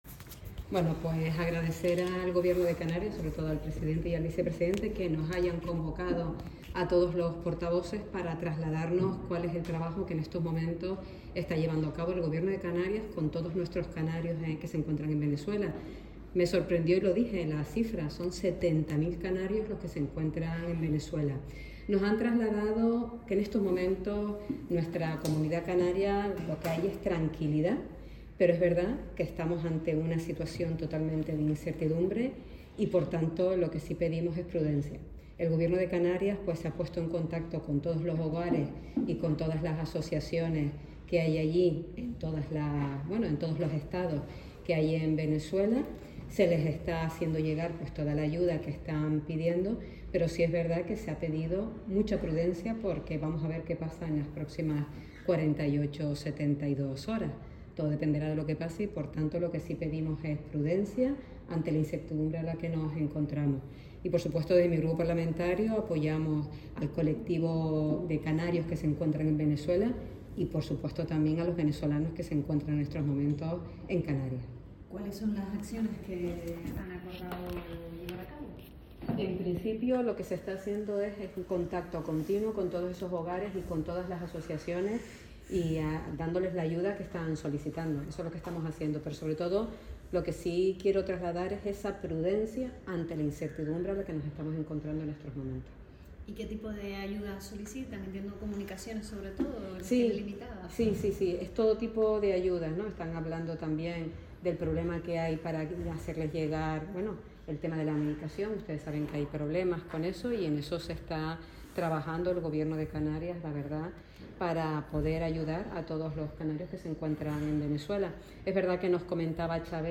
La-portavoz-del-Grupo-Parlamentario-Popular-Luz-Reveron-sobre-Venezuela.mp3